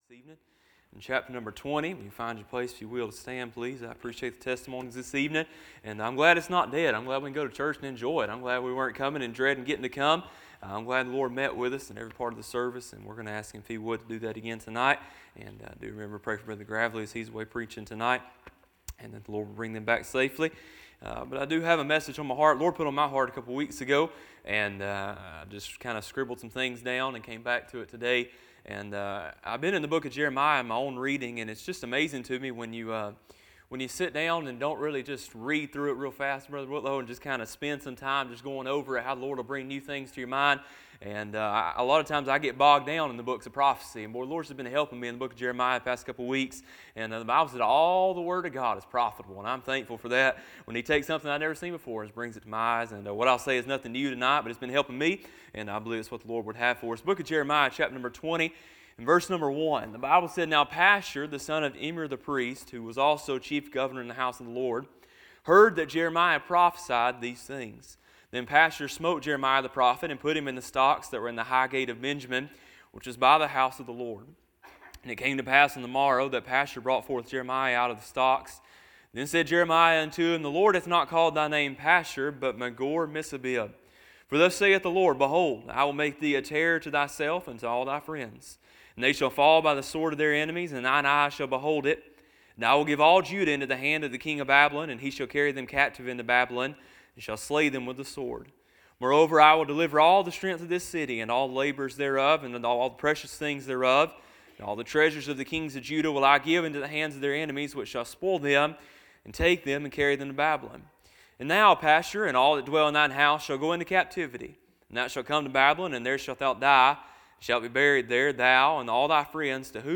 Sermons - Bible Baptist Church